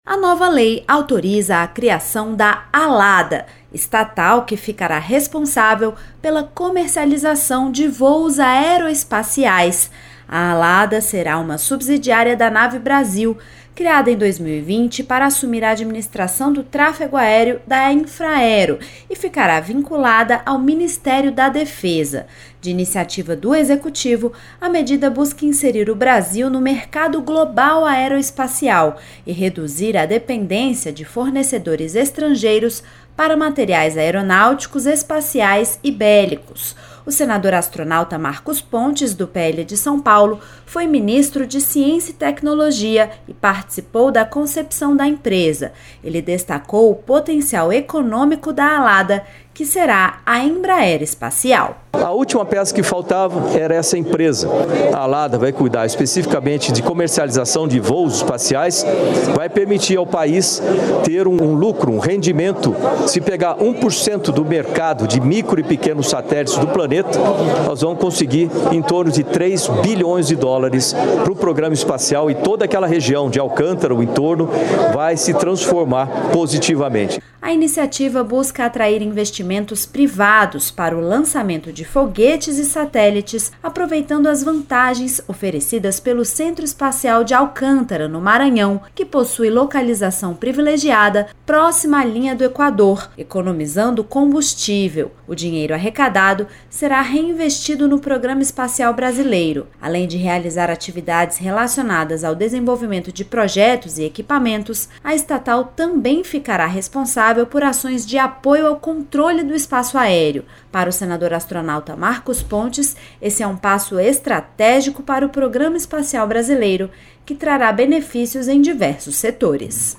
Rádio Senado : Notícias : 2025.